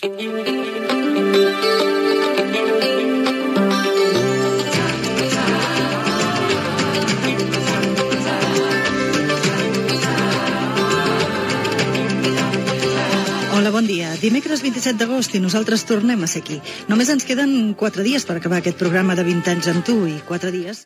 Sintonia i presentació
Entreteniment